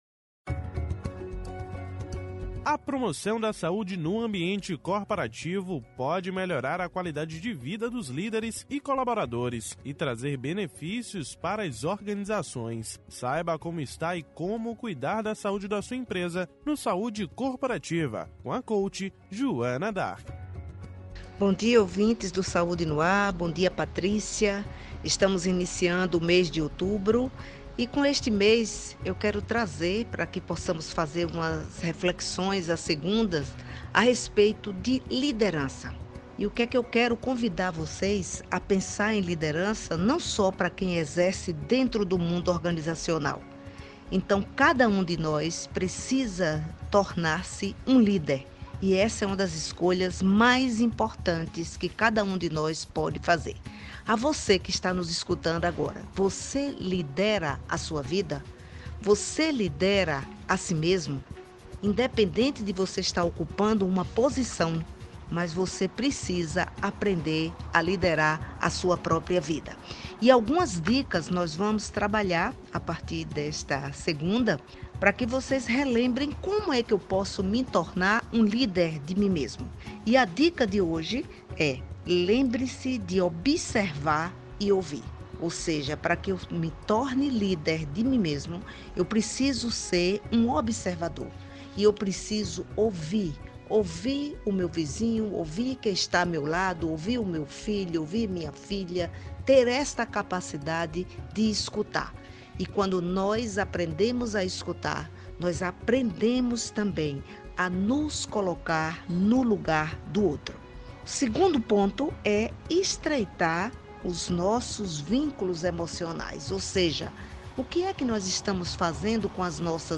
O assunto foi tema do Quadro “Saúde Corporativa” desta segunda-feira (02/10), no Programa Saúde no Ar.